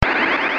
الأقسام : Electronica
تسميات : message tone sound effect melodious chimes magical